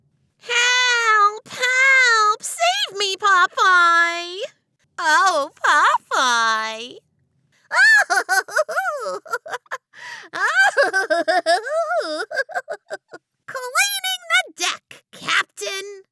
Female
Yng Adult (18-29), Adult (30-50)
Character / Cartoon
Words that describe my voice are animated, friendly, dramatic.